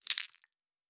Fantasy Interface Sounds
Dice Shake 10.wav